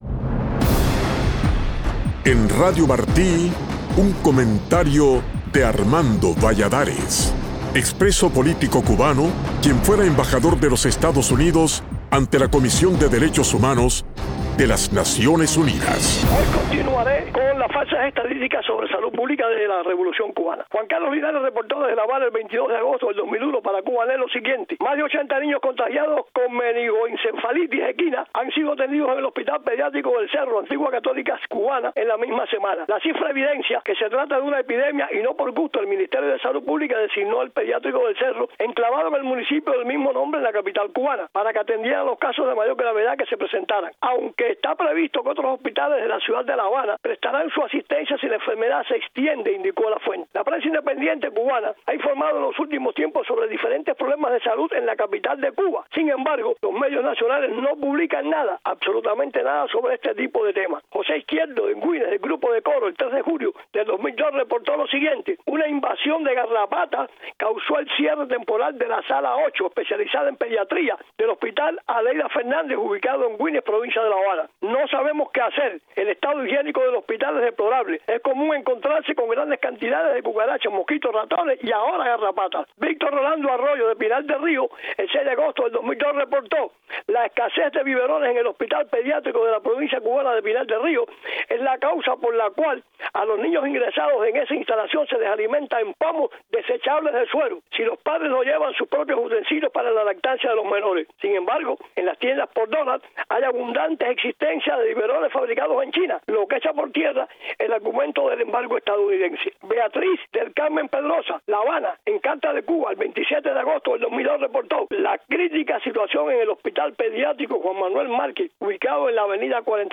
El embajador Armando Valladares continúa abordando en su comentario de hoy el tema de las falsas estadísticas de Salud Pública difundidas por la revolución cubana.